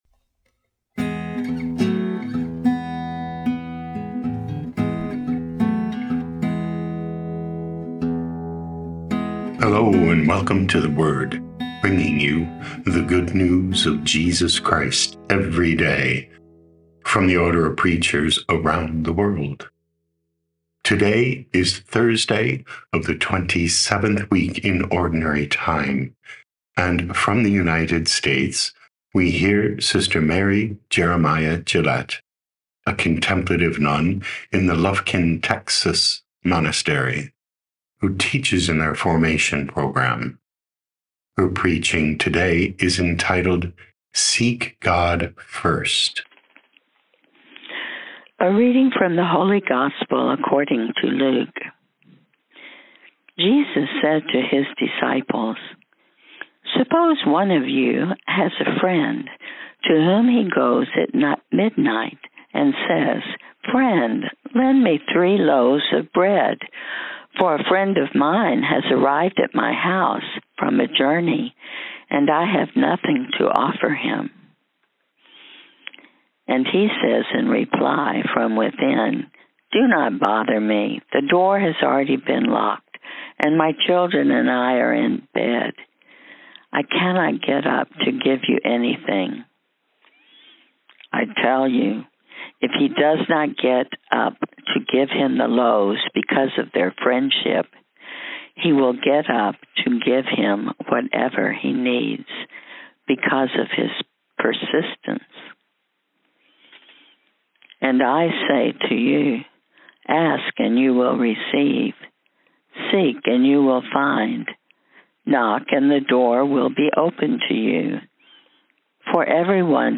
9 Oct 2025 Seek God First Podcast: Play in new window | Download For 9 October 2025, Thursday of week 27 in Ordinary Time, based on Luke 11:5-13, sent in from Lufkin, Texas, USA.
OP Preaching Tags